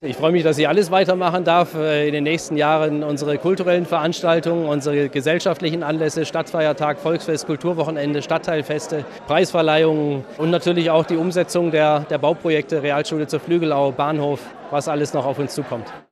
Christoph Grimmer, Oberbürgermeister von Crailsheim